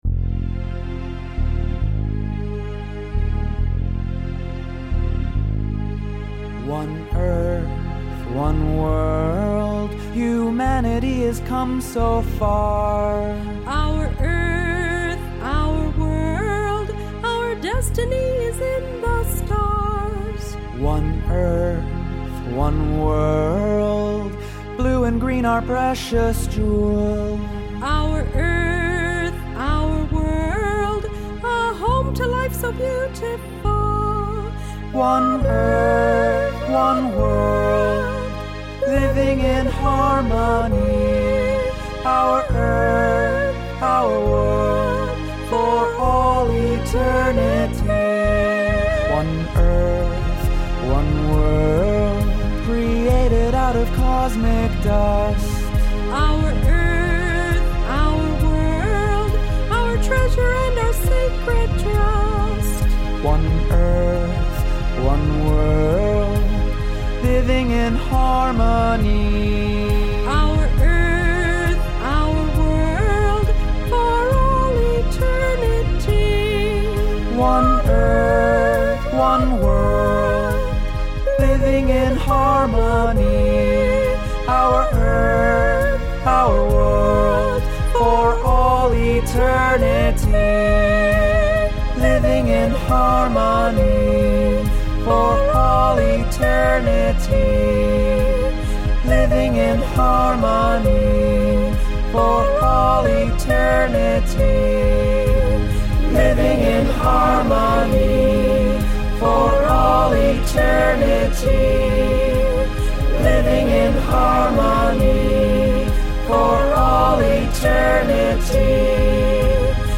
the new anthem
A duet with soprano
was recorded and mixed